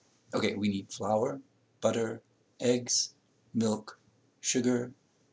Examples with Bookended Narrow Pitch